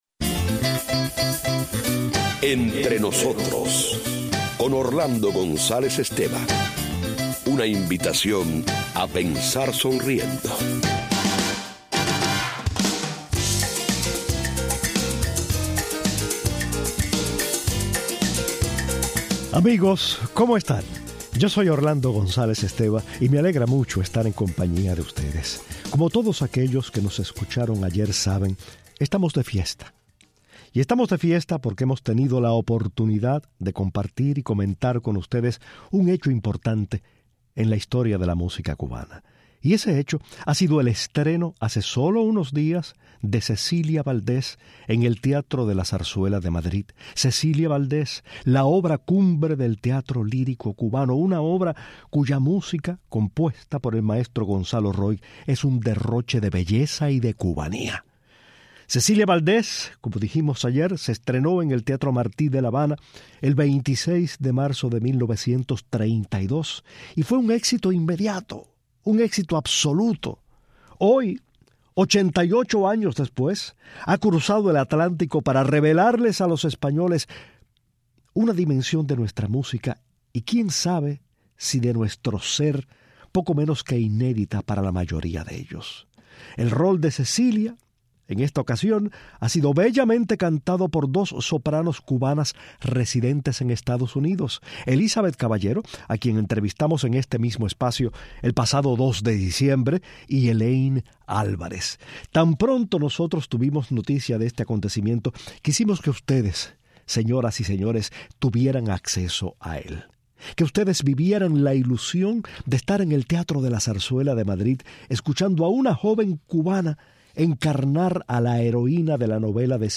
Una transmisión en vivo de "Cecilia Valdés" desde el Teatro de la Zarzuela de Madrid y la autorización del teatro para compartir algunas selecciones musicales de la presentación nos animan a destacar la cubanía de la obra y algunos detalles curiosos relacionados con ella